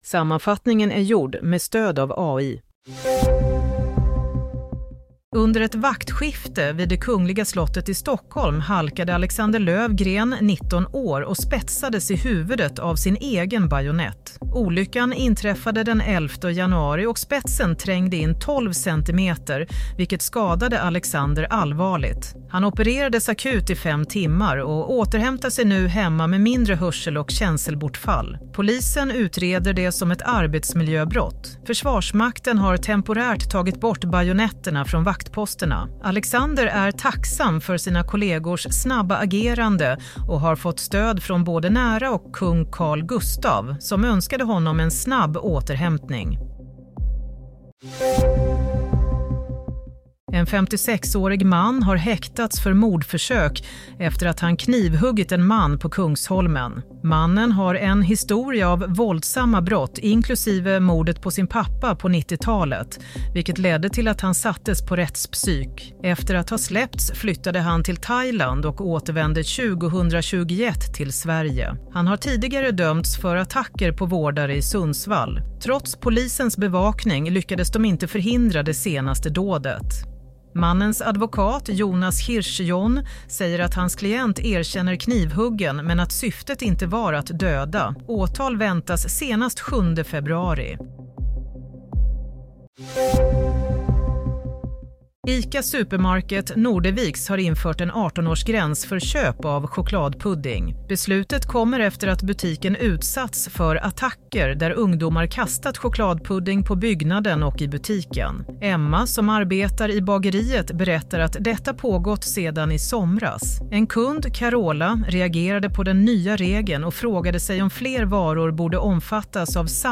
Nyhetssammanfattning – 25 januari 22.00